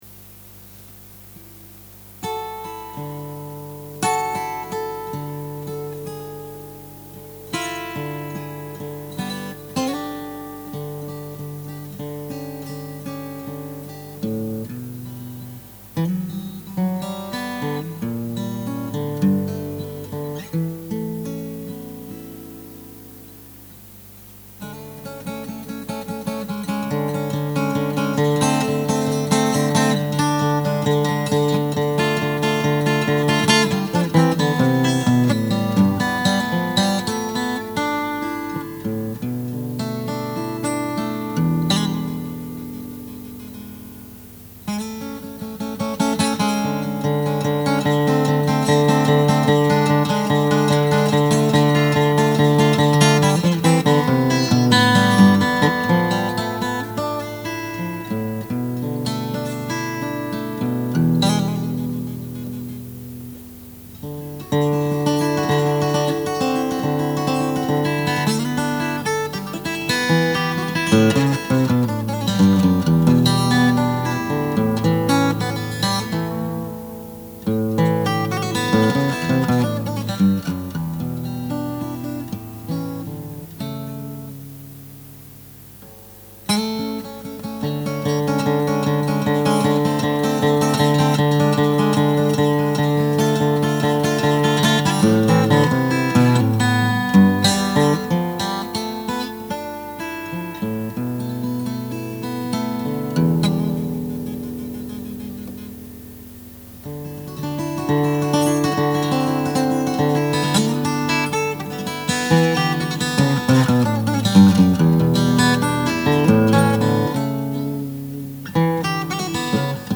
A guitar piece I've had hanging around for decades. This is just a demo version, but I have an Idea...